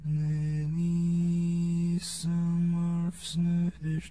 sitw.006.drippings-from-ceiling.wav